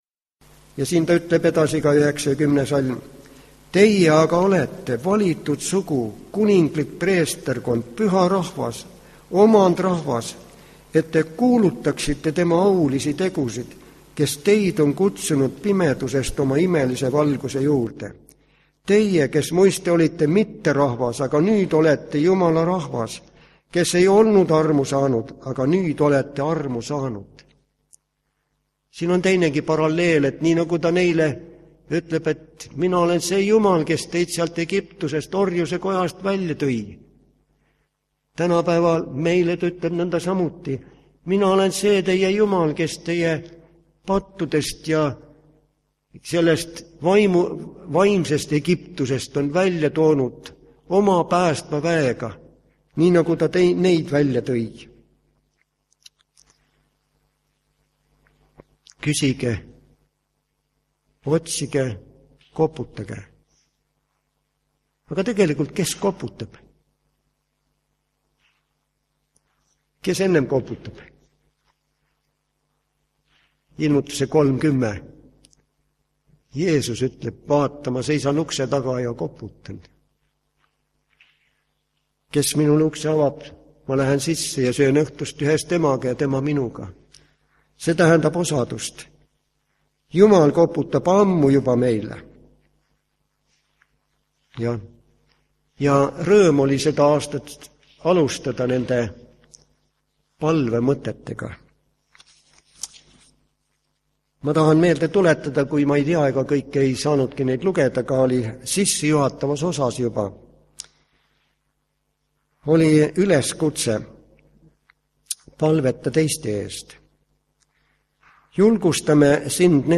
POOLIK KOOSOLEK (SALVESTIS LÄKS KATKI)
Kõne algusest puudu umbes 15 minutit. Jutlus